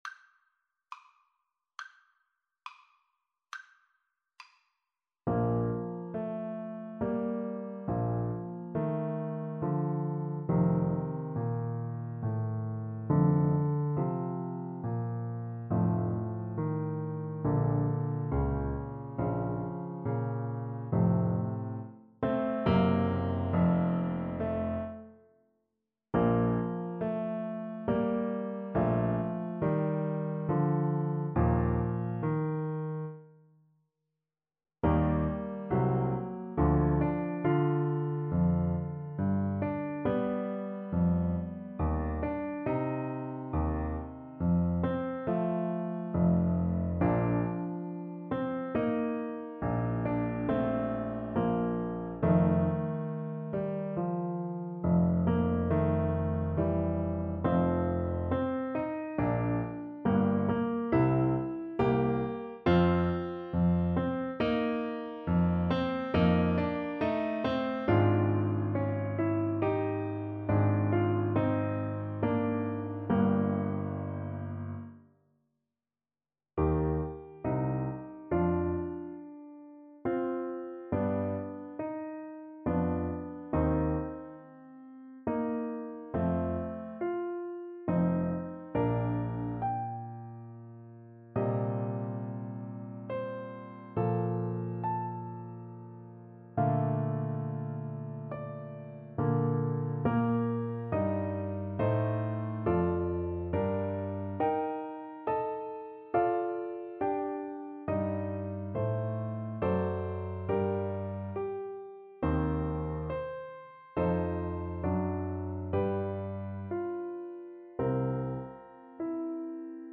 Play (or use space bar on your keyboard) Pause Music Playalong - Piano Accompaniment Playalong Band Accompaniment not yet available reset tempo print settings full screen
Bb major (Sounding Pitch) (View more Bb major Music for Cello )
Andante =c.84 =69
6/4 (View more 6/4 Music)
Classical (View more Classical Cello Music)